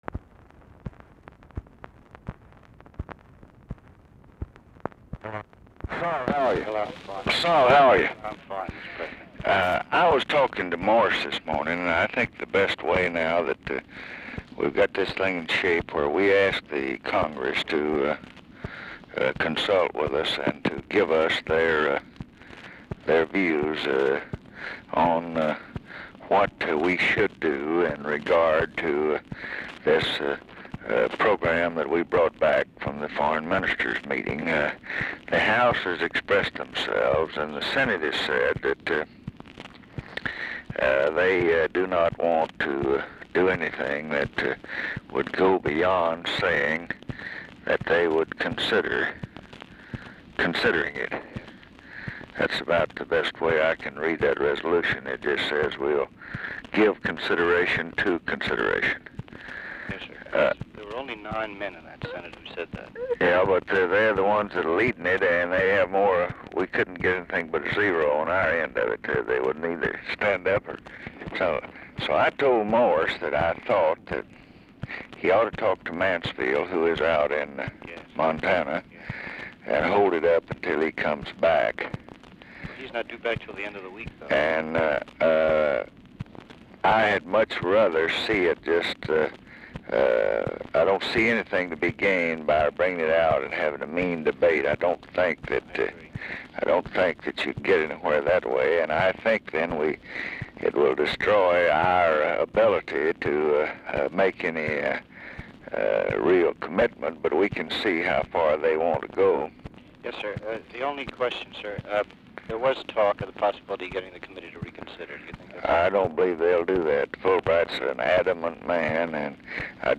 Telephone conversation # 11701, sound recording, LBJ and SOL LINOWITZ, 4/4/1967, 8:35AM | Discover LBJ